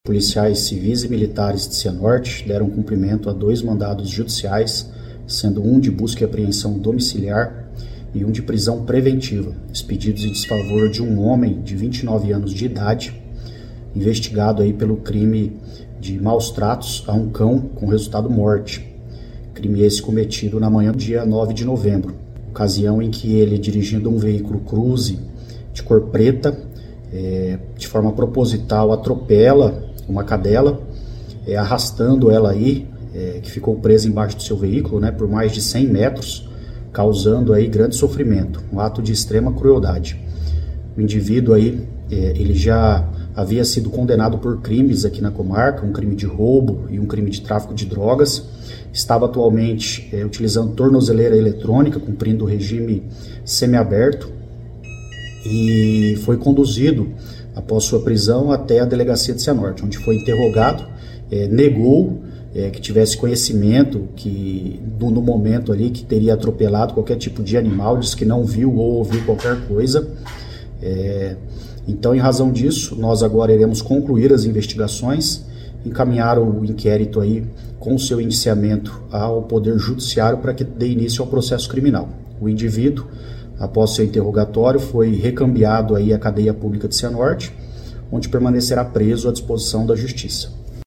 Ouça o que diz o delegado de Cianorte Carlos Stecca: